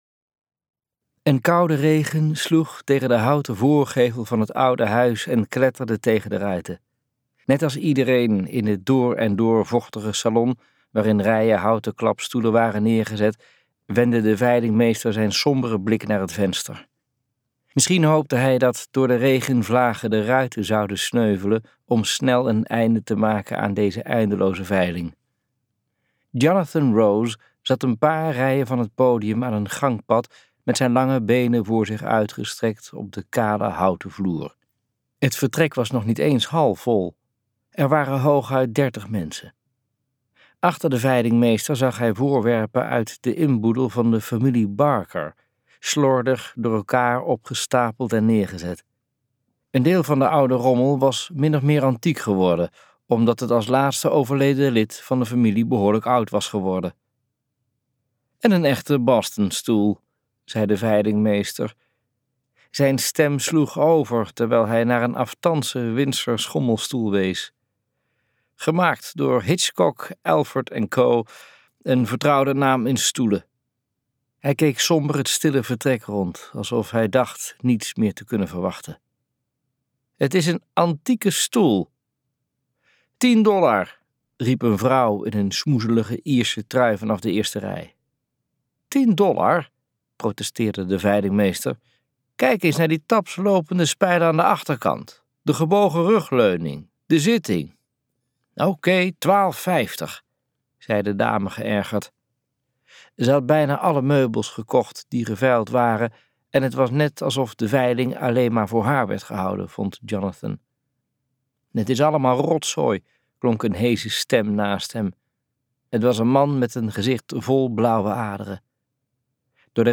The war of the roses luisterboek | Ambo|Anthos Uitgevers